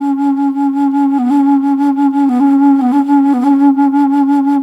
Flute 51-12.wav